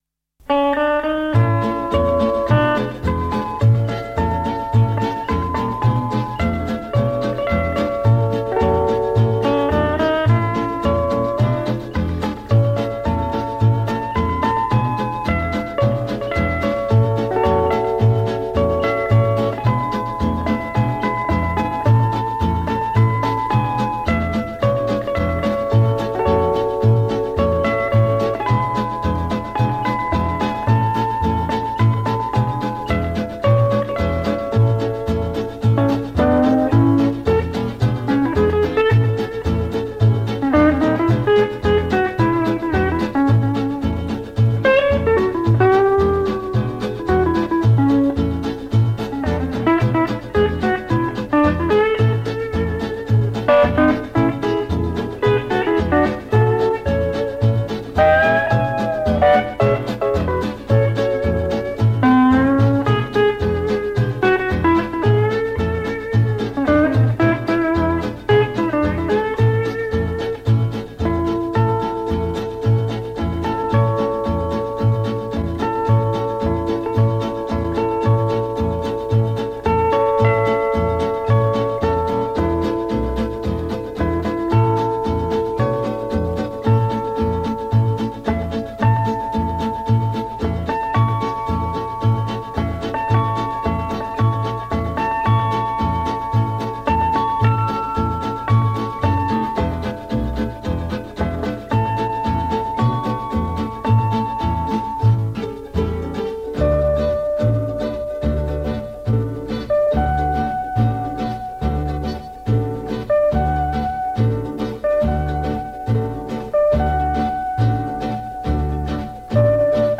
Гавайские ритмы для вечеринки